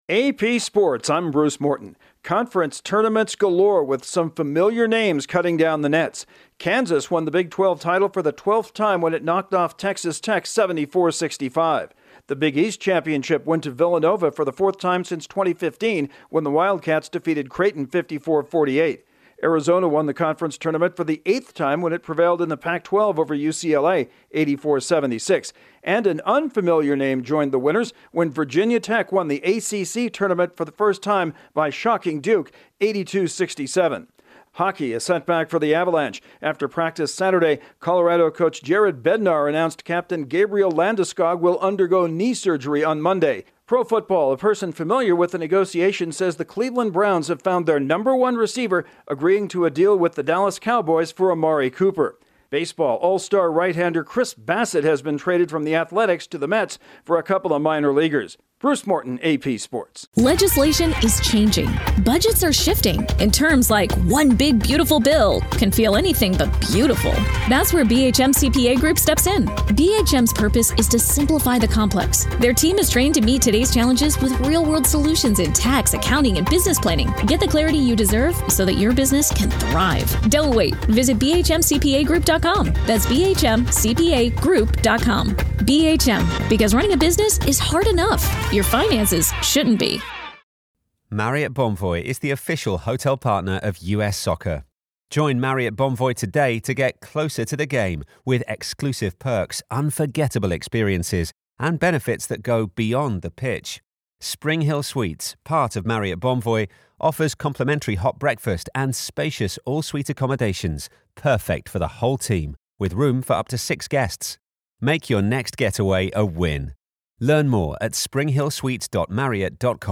Kansas, Villlanova, Arizona and Virginia Tech are conference champs, the Avalanche lose a key player, the Browns get a prominent receiver and the Mets find pitching help. Correspondent